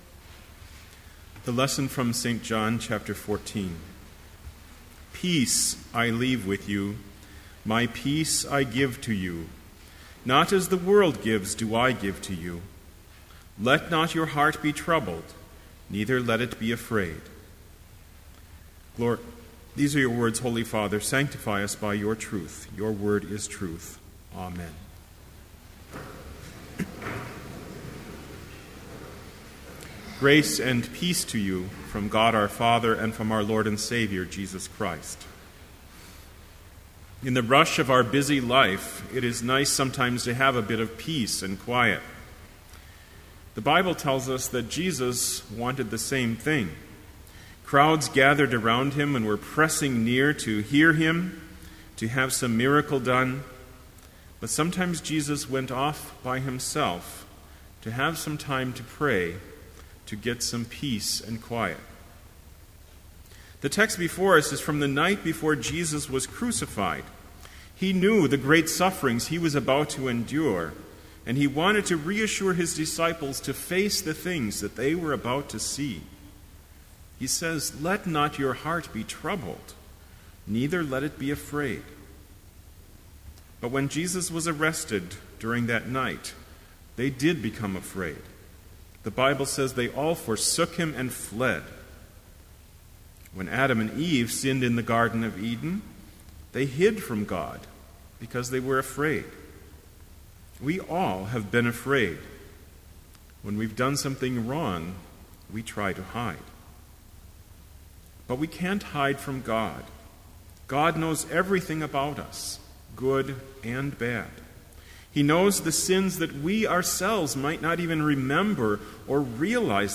Sermon Only
This Chapel Service was held in Trinity Chapel at Bethany Lutheran College on Wednesday, October 10, 2012, at 10 a.m. Page and hymn numbers are from the Evangelical Lutheran Hymnary.